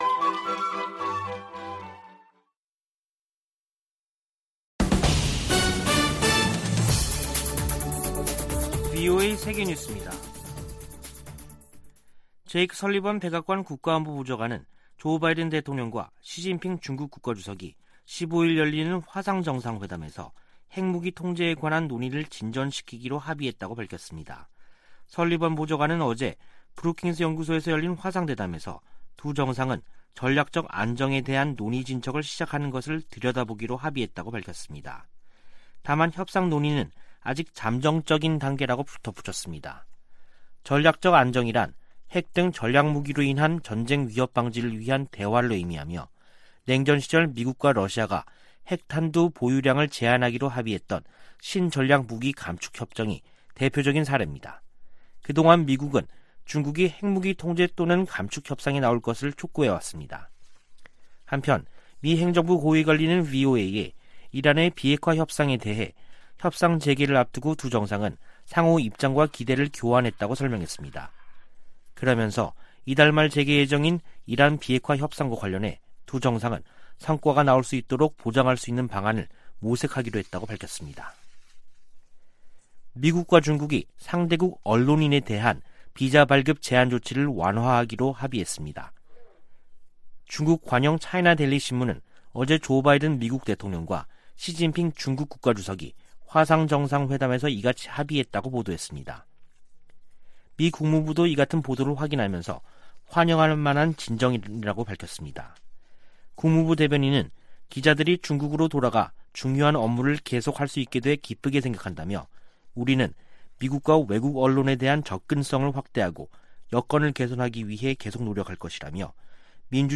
VOA 한국어 간판 뉴스 프로그램 '뉴스 투데이', 2021년 11월 17일 3부 방송입니다. 미국의 백악관 국가안보보좌관은 북한 문제는 이란 핵과 함께 당면한 도전과제로 중국과의 긴밀한 조율이 중요하다고 밝혔습니다.